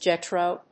/dʒétroʊ(米国英語), dʒétrəʊ(英国英語)/